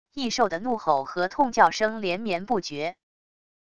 异兽的怒吼和痛叫声连绵不绝wav音频